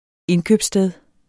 Udtale [ ˈenkøbs- ]